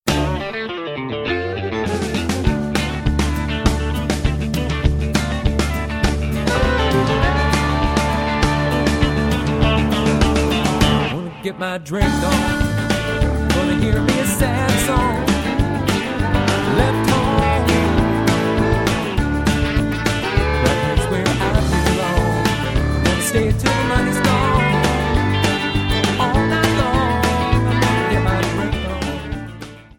Backing track Karaoke
Country, 2000s